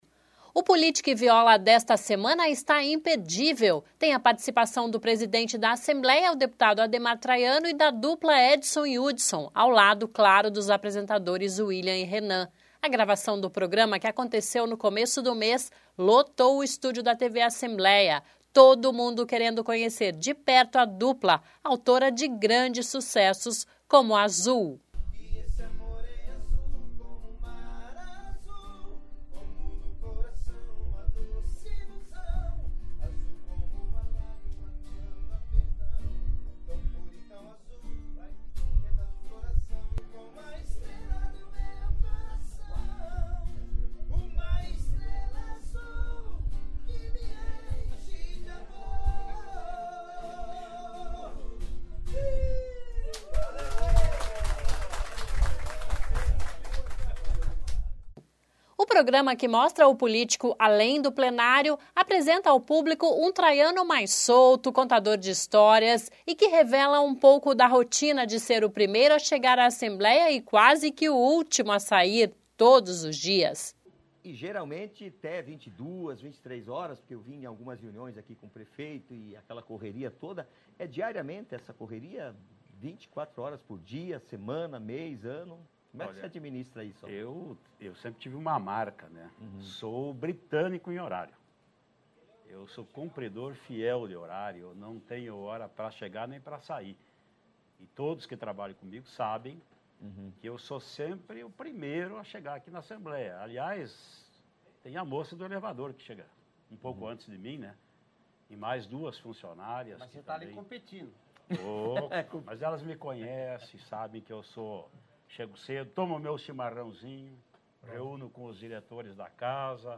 (Sobe som)
(Sonora)